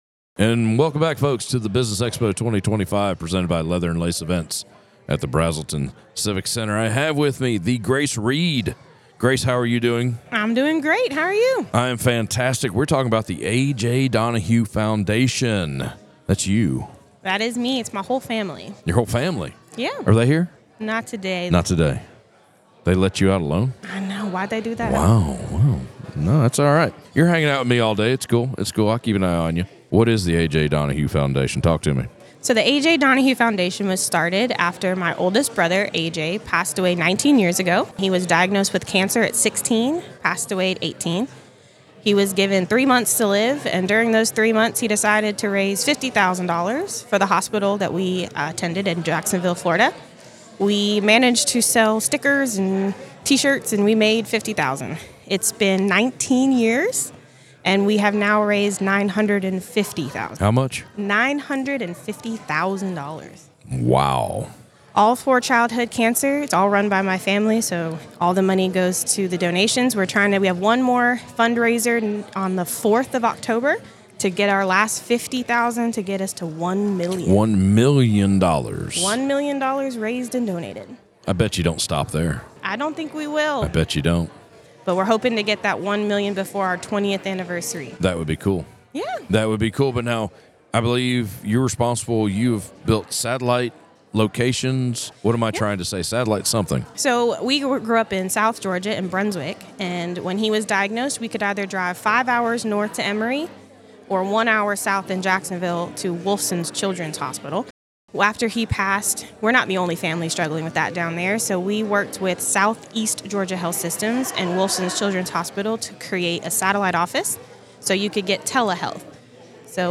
Business Expo 2025 presented by Leather & Lace Events at the Braselton Civic Center
Northeast Georgia Business RadioX – the official Podcast Studio of the Business Expo 2025